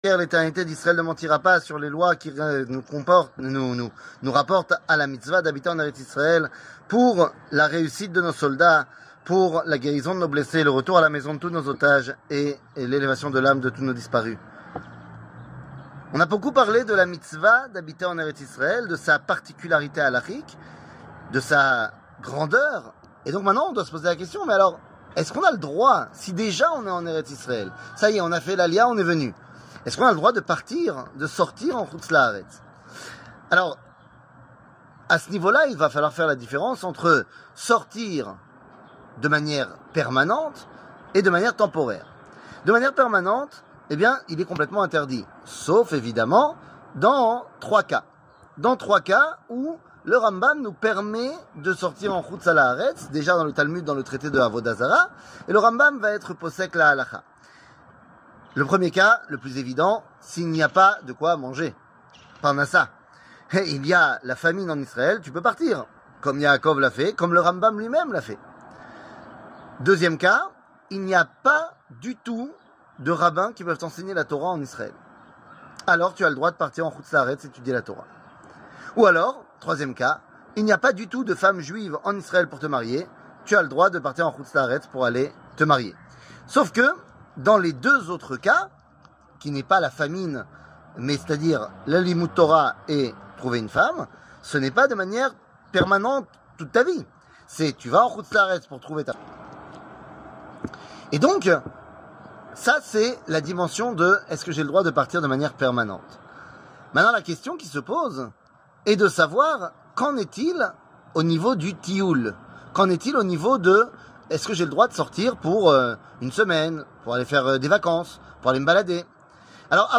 L'éternité d'Israel ne mentira pas ! 15 00:04:43 L'éternité d'Israel ne mentira pas ! 15 שיעור מ 26 אוקטובר 2023 04MIN הורדה בקובץ אודיו MP3 (4.32 Mo) הורדה בקובץ וידאו MP4 (7.06 Mo) TAGS : שיעורים קצרים